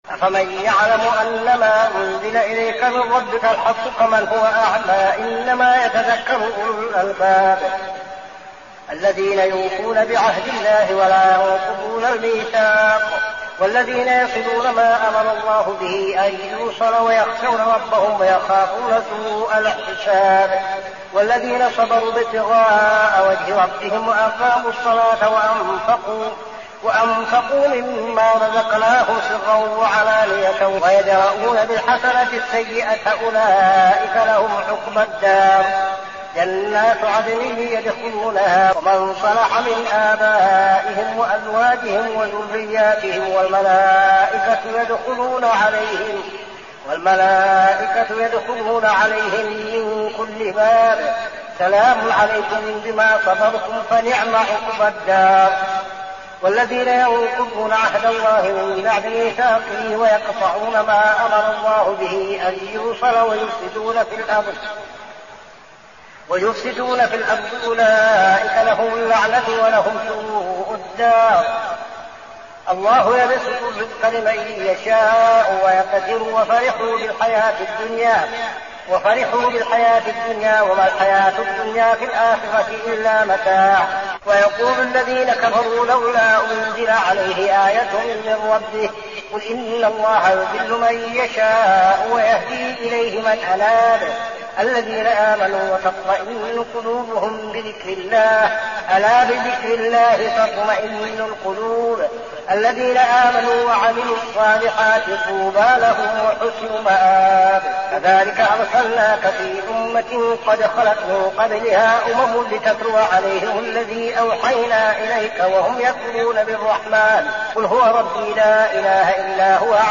صلاة التراويح عام 1402هـ سورتي الرعد 19-43 و إبراهيم كاملة | Tarawih prayer Surah Ar-Ra'd and Ibrahim > تراويح الحرم النبوي عام 1402 🕌 > التراويح - تلاوات الحرمين